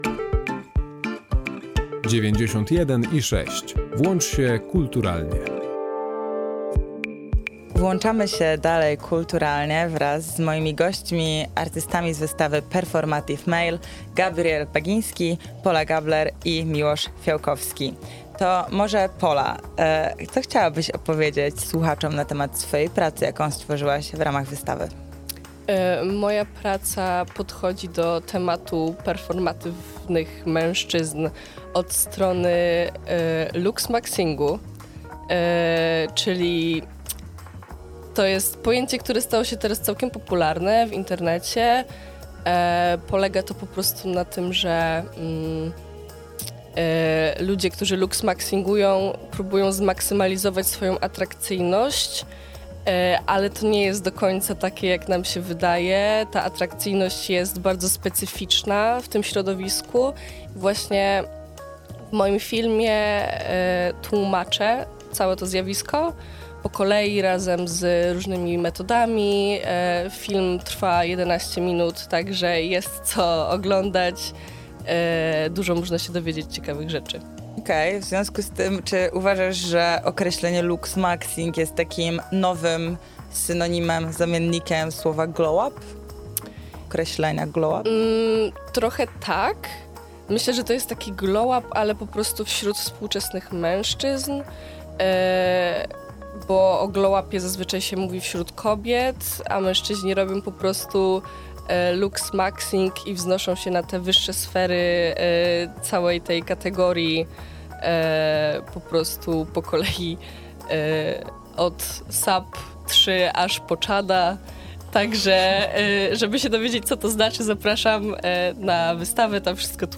Więcej o wystawie i całym zjawisku mówiliśmy w ramach audycji Pełna Kultura: